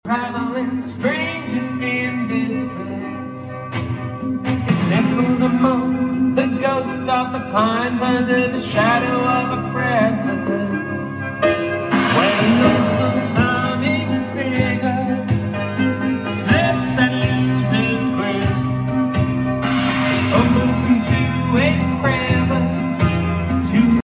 ✨ Vintage 1963 Restored Precedent Radiogram ✨